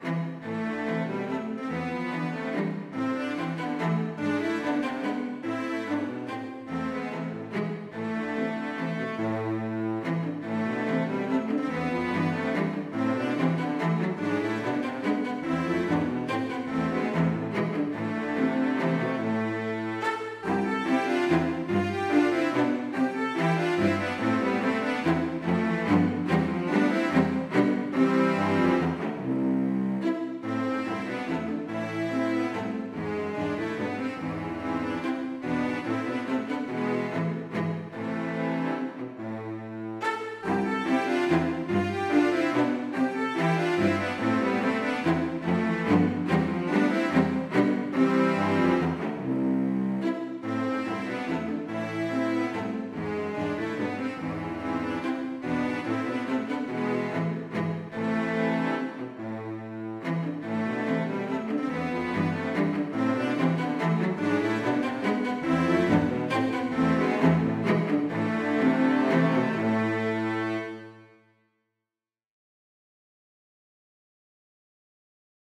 Lydfilene er digitalt produsert i noteprogrammet og er bare illustrasjoner.
• CELLOKVARTETT
- Bang 121b: Caveler Dantz for cello kvartett   Note